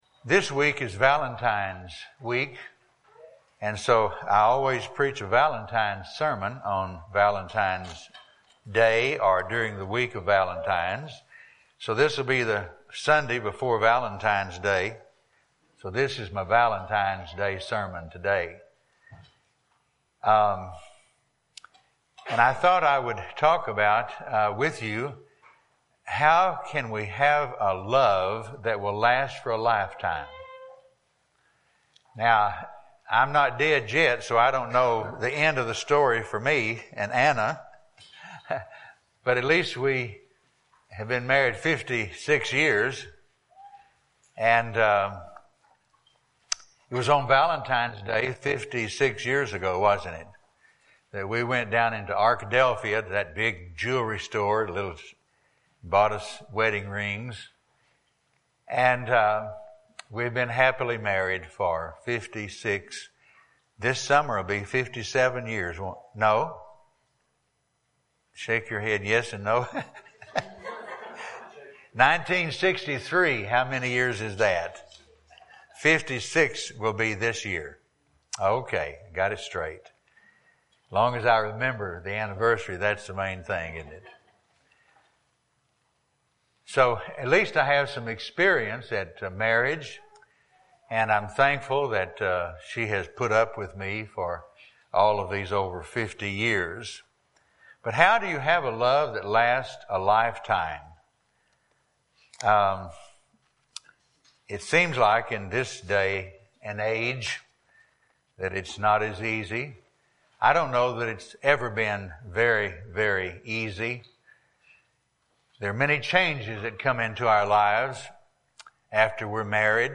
Passage: 1 Corinthians 13:4-7 Service Type: Sunday Morning